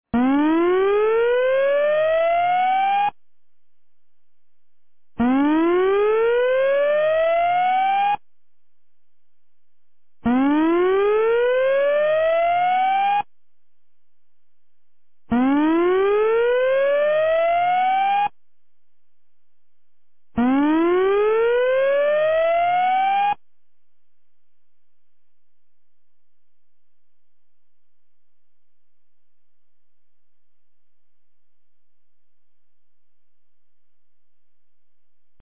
■防災行政無線情報■ | 三重県御浜町メール配信サービス
放送音声